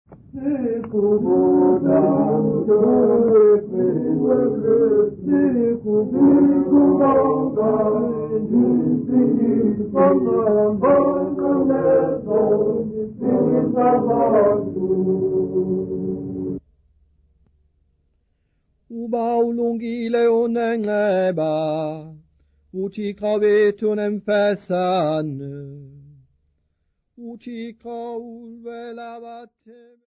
Church music South Africa
Hymns, Xhosa South Africa
Folk music South Africa
field recordings
A traditional hymn, accompanied by clapping